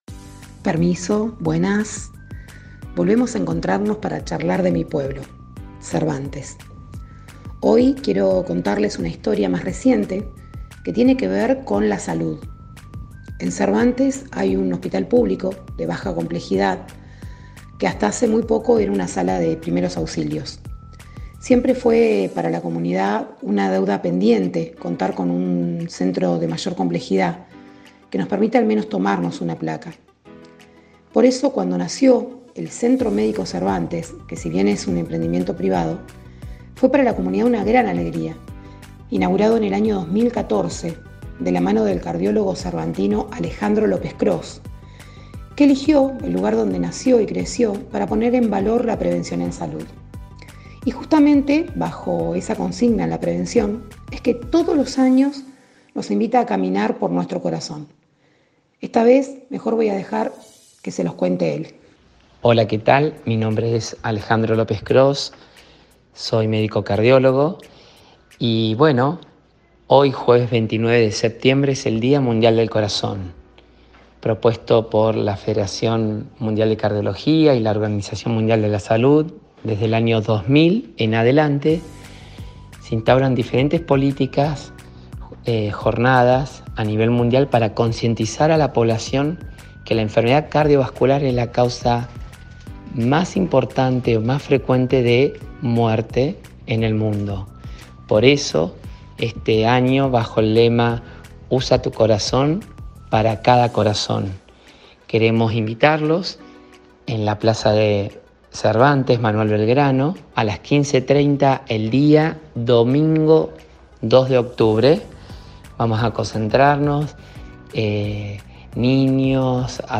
habla de este evento que convoca a la mayoría de esta comunidad del Alto Valle.